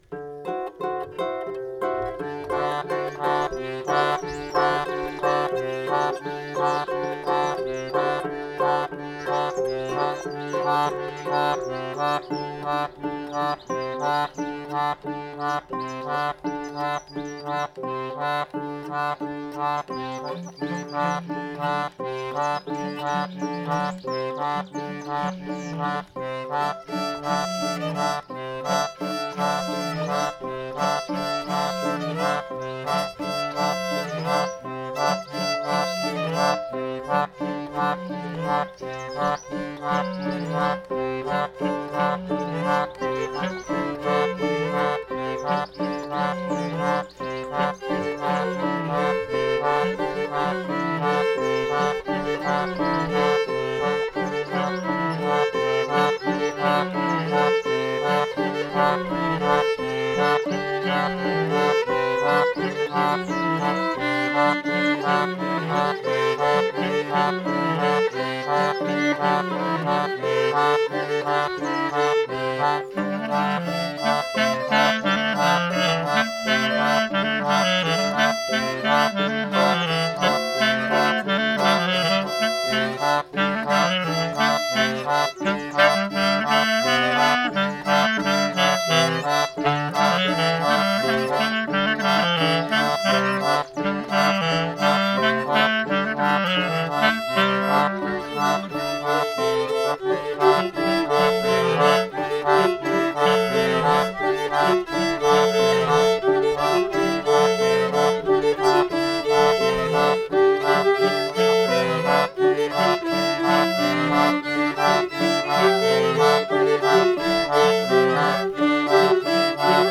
04_hanter_dro.mp3